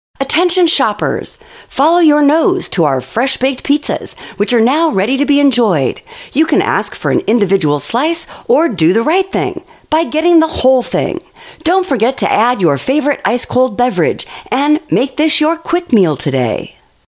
You can upload your own messages or use our professional voiceover
Female 1